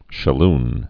(shə-ln, shă-)